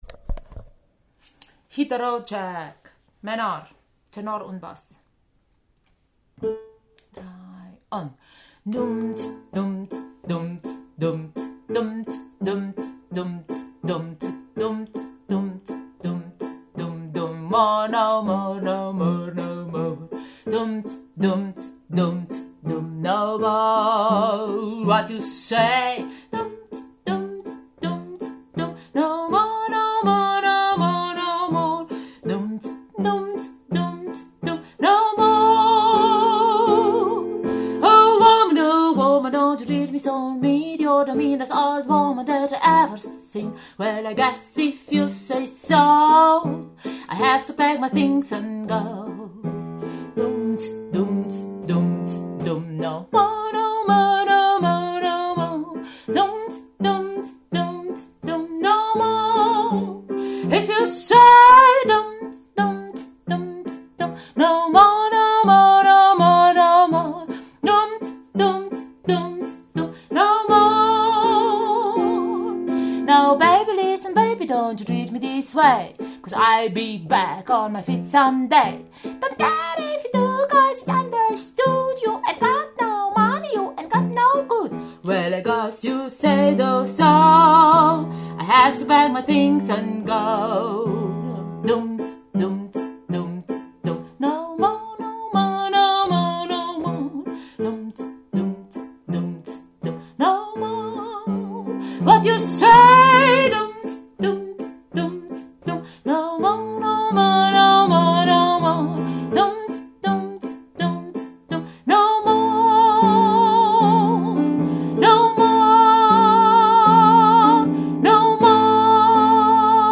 Hit the road Jack – Tenor / Bass
Hit-the-Road-Jack-Tenor+Bass.mp3